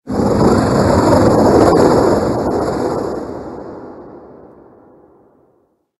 Cri d'Angoliath Gigamax dans Pokémon HOME.
Cri_0861_Gigamax_HOME.ogg